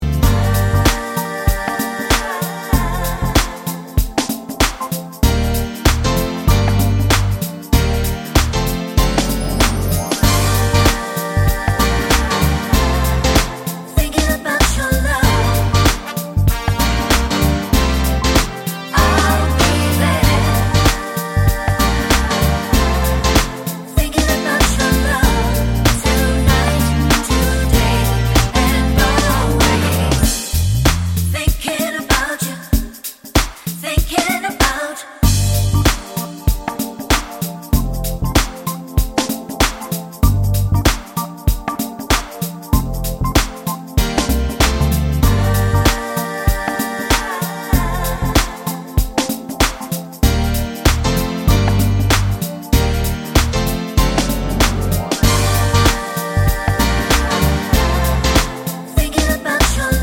no Backing Vocals Pop (1990s) 3:59 Buy £1.50